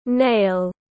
Móng tay móng chân tiếng anh gọi là nail, phiên âm tiếng anh đọc là /neɪl/.
Nail /neɪl/